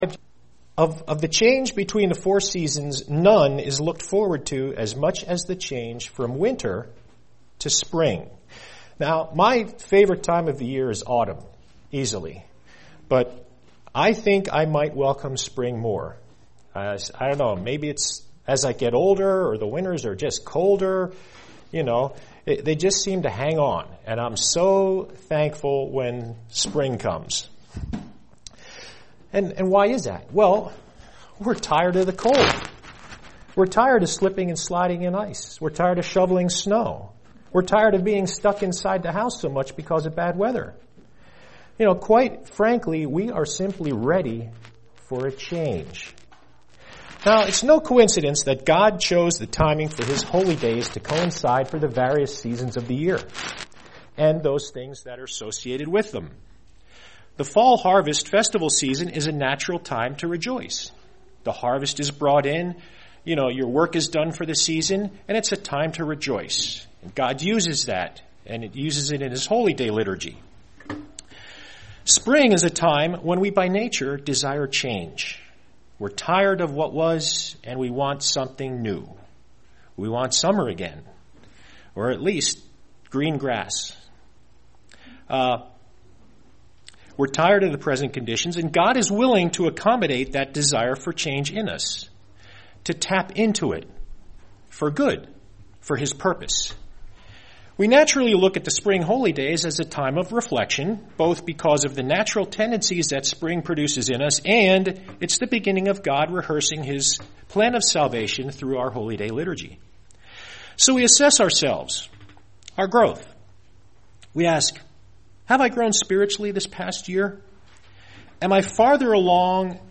Given in Lehigh Valley, PA
UCG Sermon Studying the bible?